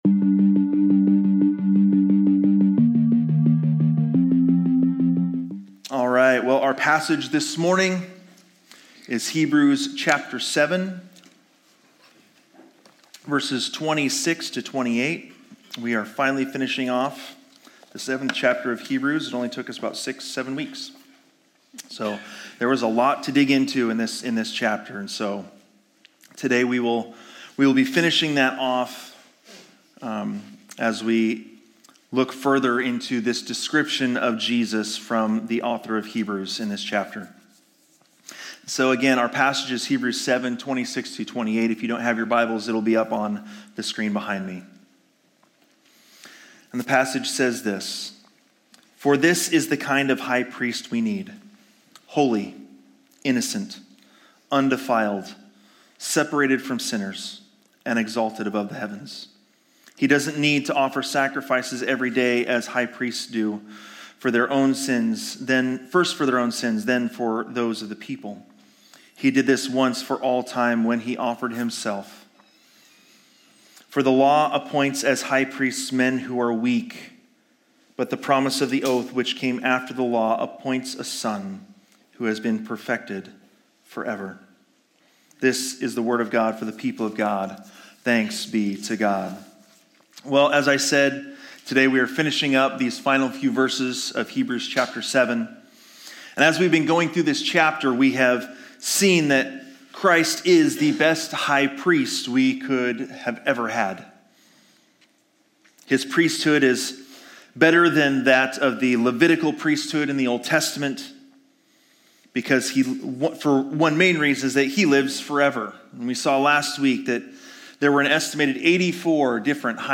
This sermon will help you understand the depth of your sin, the greatness of Jesus’ sacrifice, and the urgent call to turn from self-reliance and “general spirituality” to real repentance and faith in the One who was made sin for us so that we might become the righteousness of God.